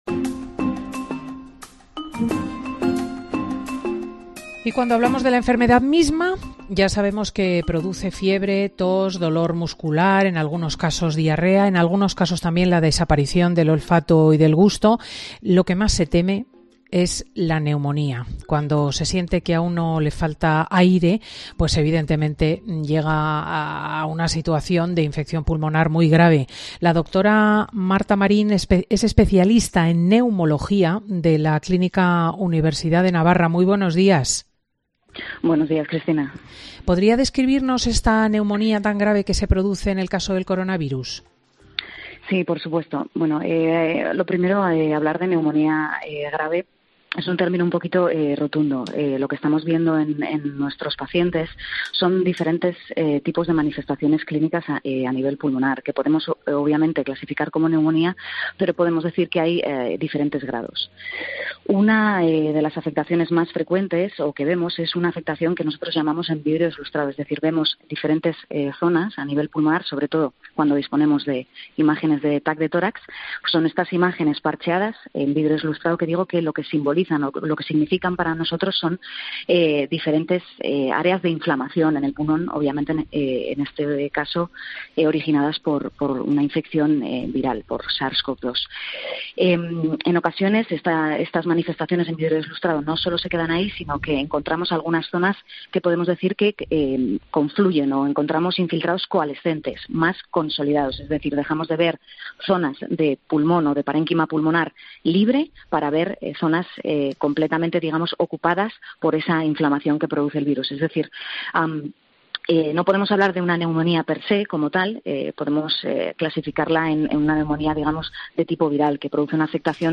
médica especialista en neumología con dedicación preferencial al estudio de la enfermedad pulmonar obstructiva crónica